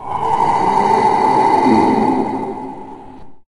zombie_eat_0.ogg